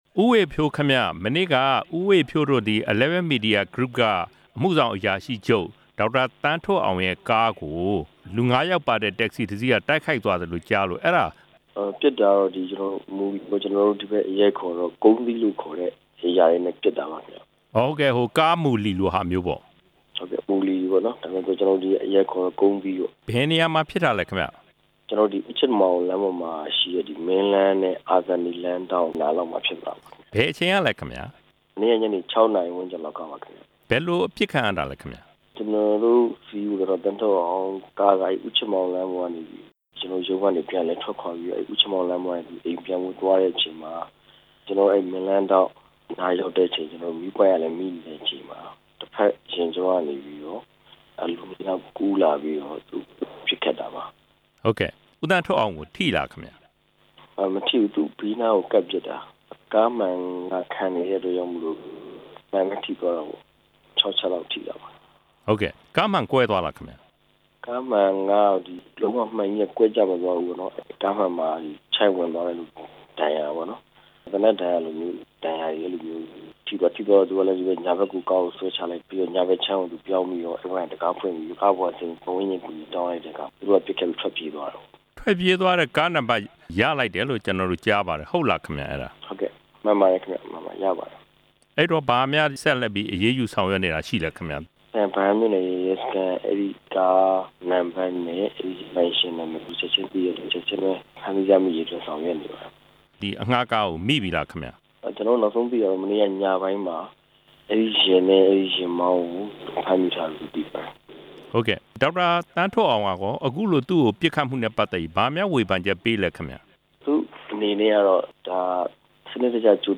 ဆက်သွယ်မေးမြန်းထားတာ